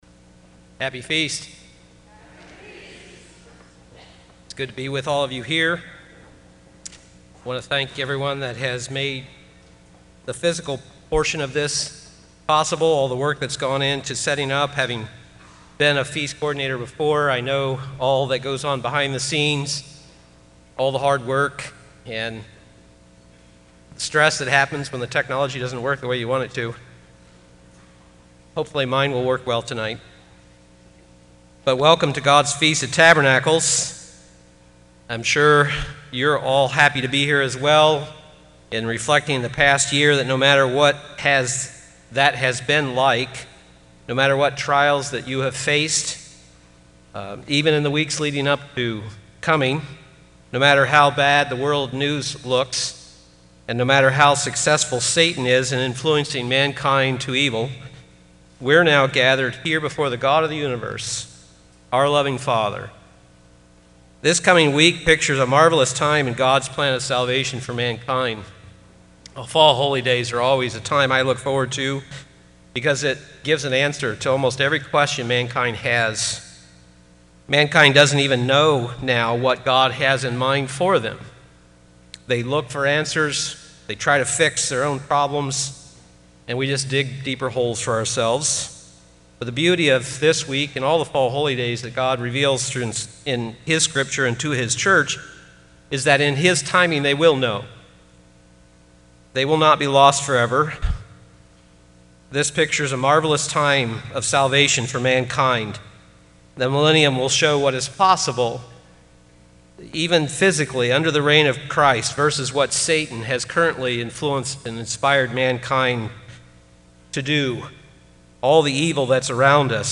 This sermon was given at the Wisconsin Dells, Wisconsin 2017 Feast site.